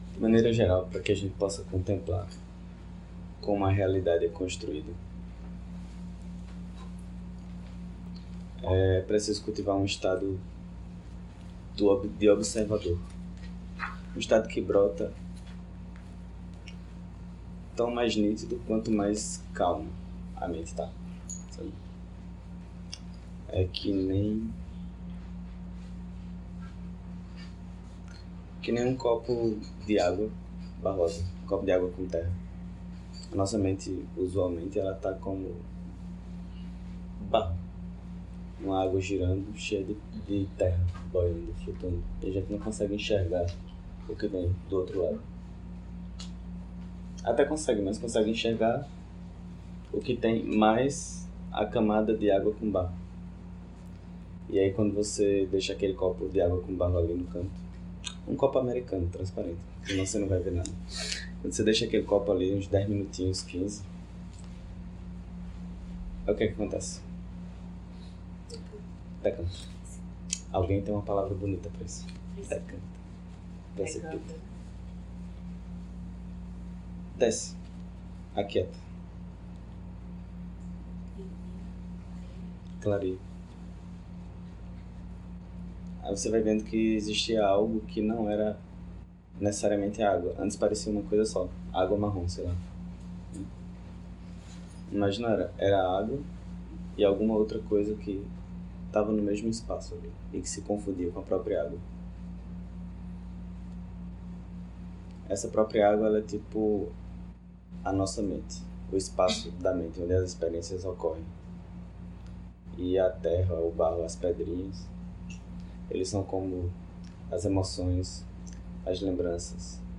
Encontro ocorrido no CEBB Recife, sala encruzilhada em 7 de fevereiro de 2019.